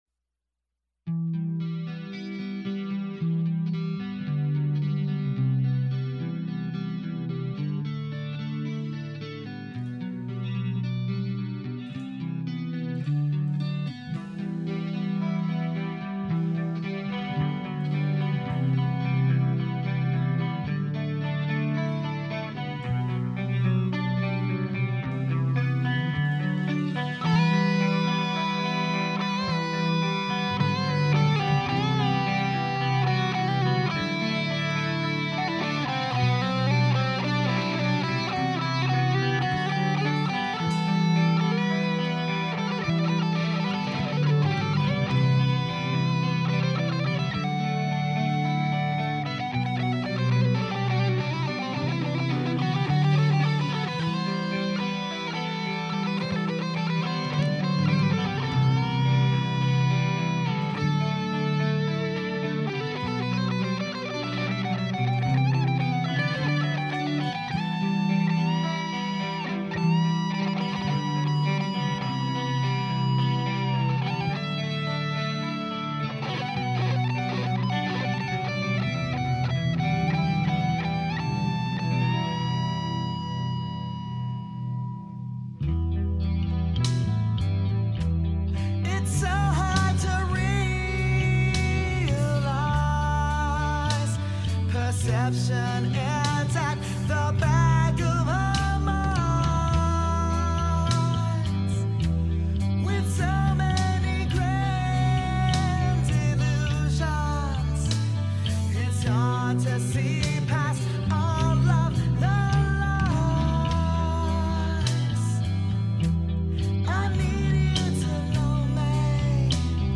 It was our most epic song as far as progression goes.
Recorded at Red Room studios Apopka FL.
rhythm guitars
Lead guitars
Music / Rock
rock metal full band guitars vocals bass drums epic progressive